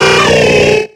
Cri de Tygnon dans Pokémon X et Y.